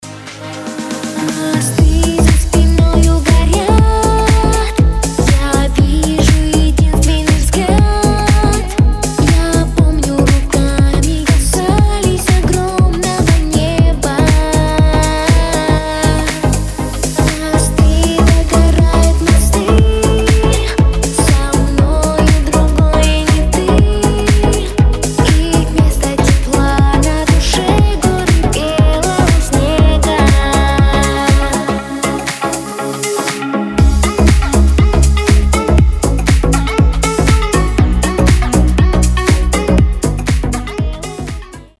• Качество: 320, Stereo
поп
грустные
dance
club
клубняк